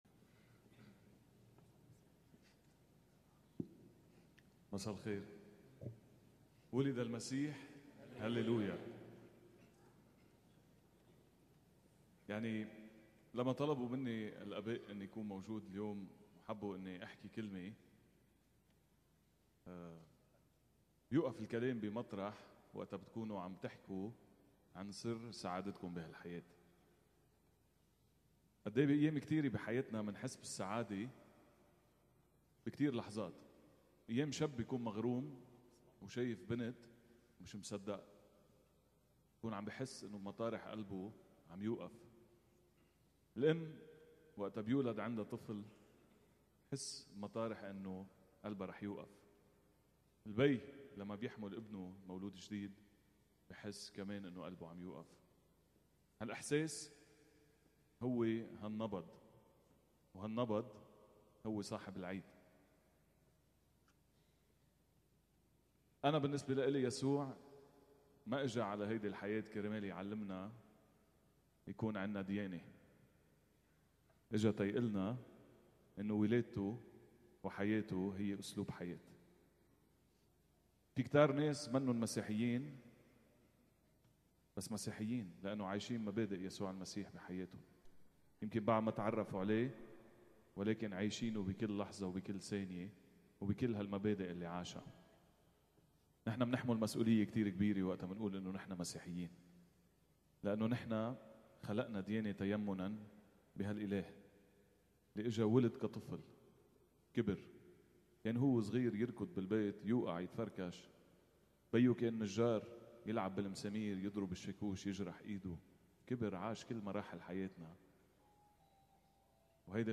منذ أيام في كنيسة “سانت تريز الطفل يسوع” في السهيلة في أسبوع عيد الميلاد: